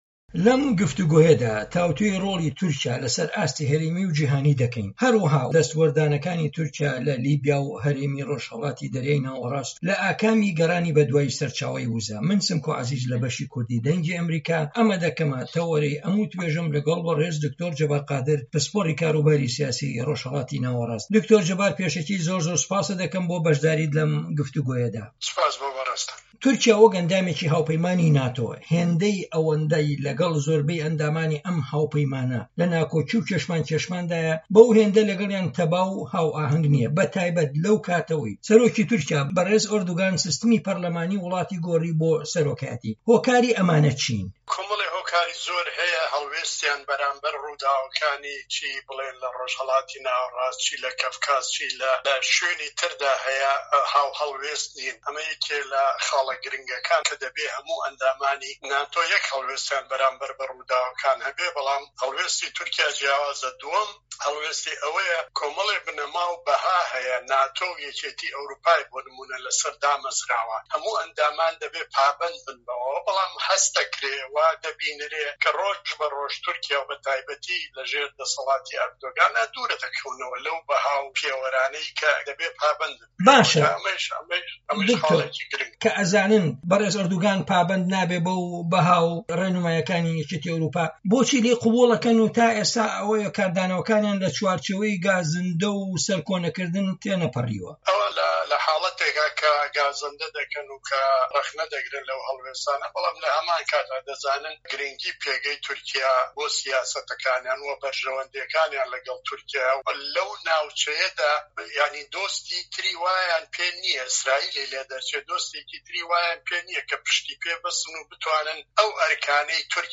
تورکیا - گفتوگۆکان